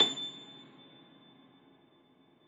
53g-pno25-A5.wav